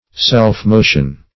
Search Result for " self-motion" : The Collaborative International Dictionary of English v.0.48: Self-motion \Self`-mo"tion\, n. Motion given by inherent power, without external impulse; spontaneous or voluntary motion.